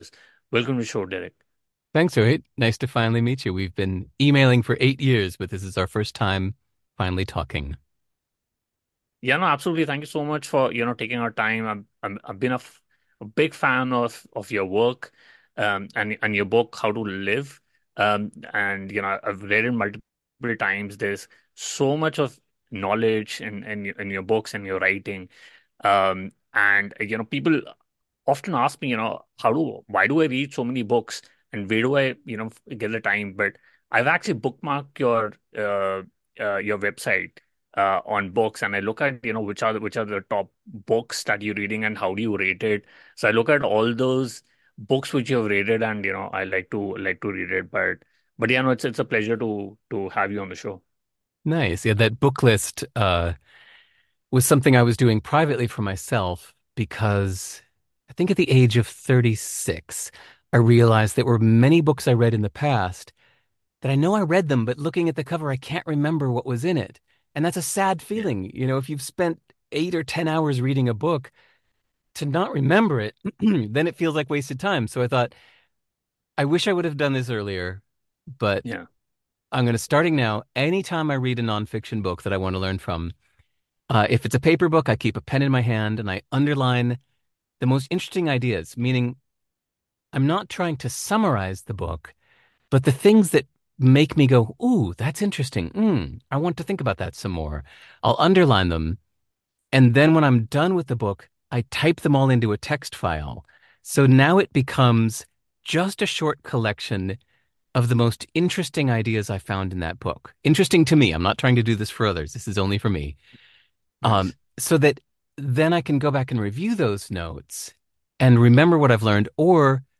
Life Self Mastery interview